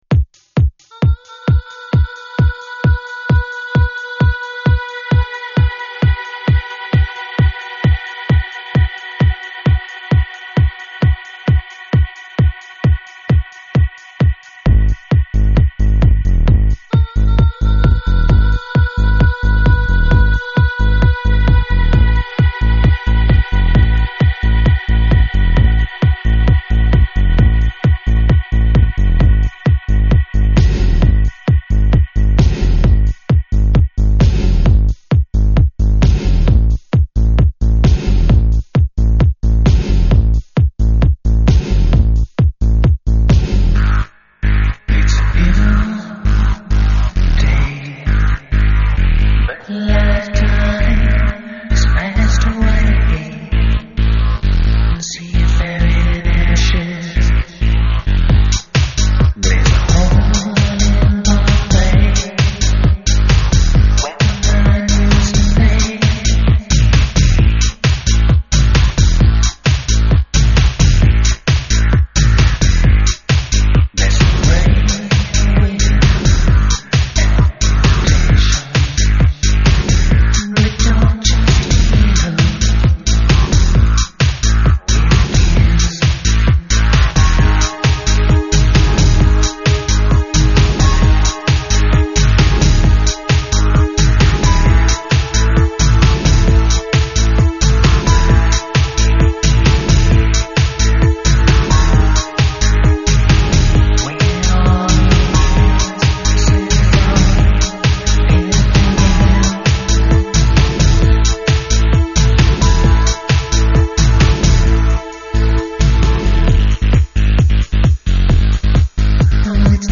Euro Rock from Seattle